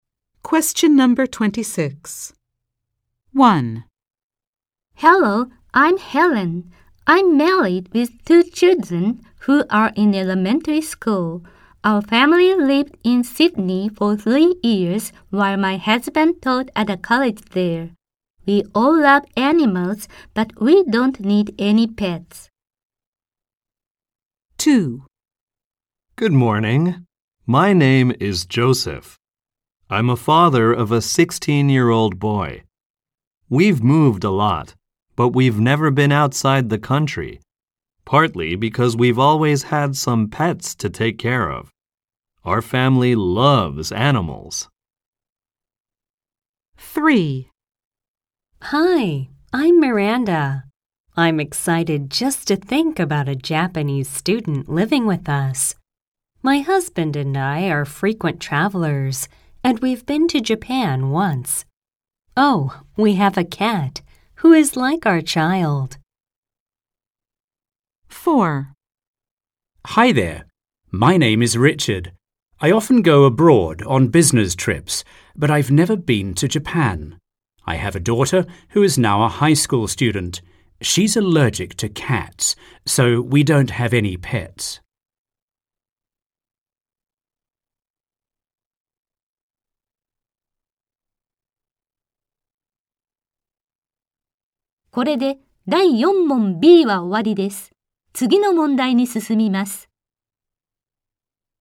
〇アメリカ英語に加えて、イギリス英語、アジア英語の話者の音声も収録しています。
ノーマル・スピード音声   ハイ・スピード音声